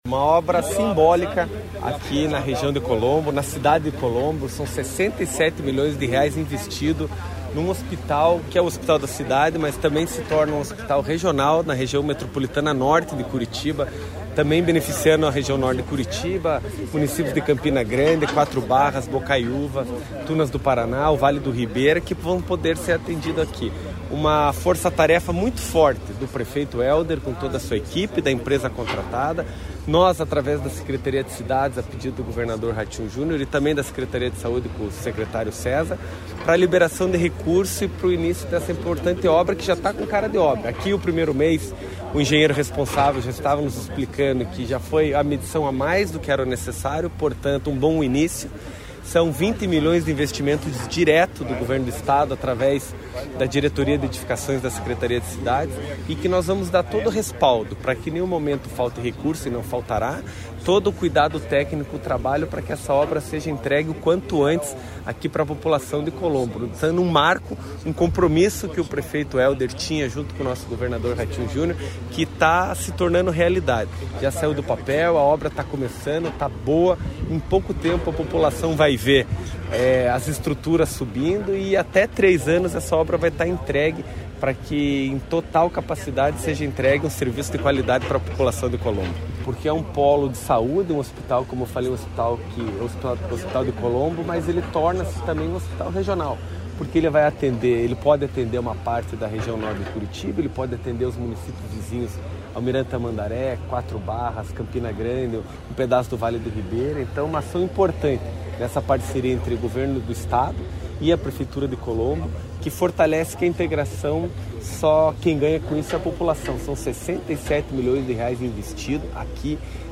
Sonora do secretário das Cidades, Eduardo Pimentel, sobre a construção do Hospital Geral de Colombo